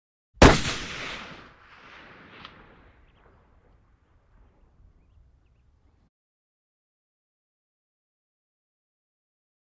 Звуки охоты
Охотник совершает выстрел